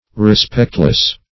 Search Result for " respectless" : The Collaborative International Dictionary of English v.0.48: Respectless \Re*spect"less\ (r[-e]*sp[e^]k"l[e^]s), a. Having no respect; without regard; regardless.